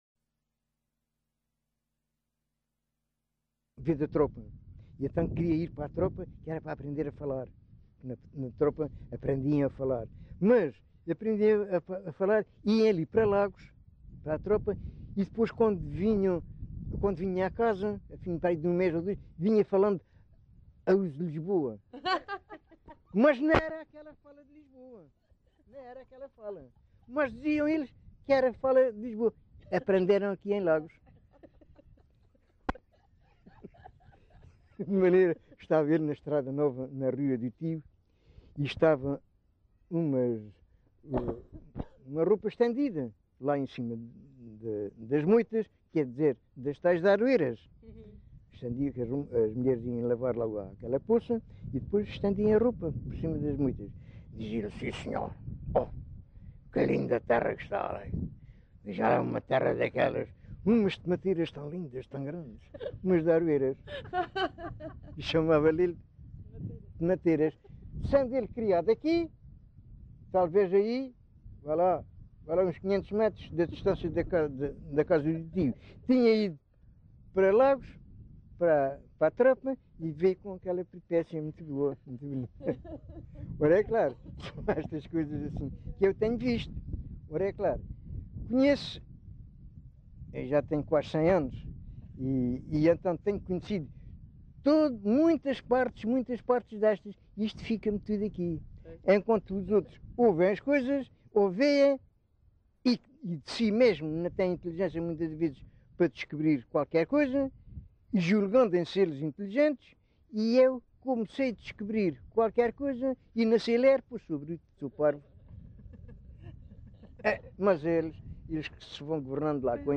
LocalidadePorches (Lagoa, Faro)